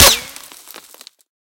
Better Bullet Cracks
bulletFlyBy_4.ogg